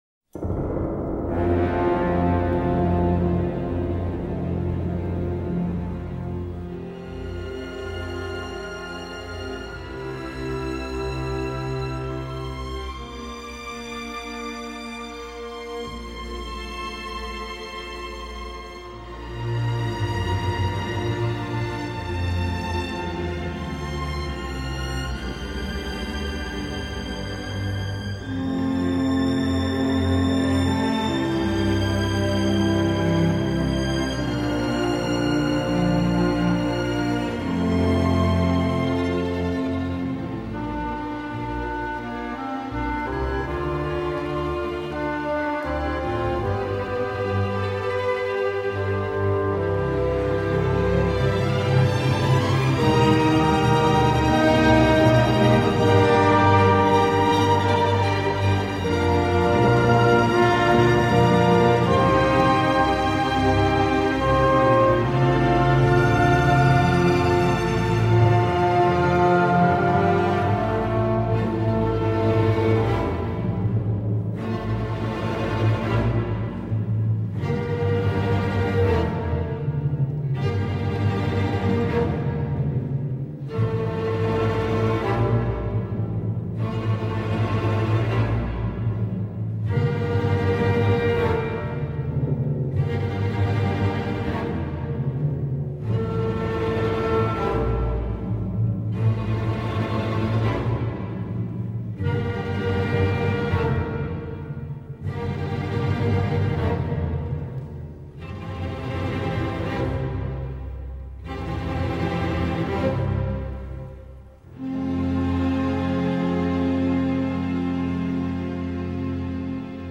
Beau doublé orchestral et choral.
avec deux belles œuvres symphoniques